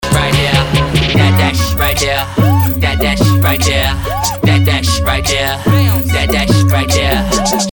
Нужен такой популярный крик
Слышал во многих треках такой характерный крик, но нигде не мог найти его отдельно.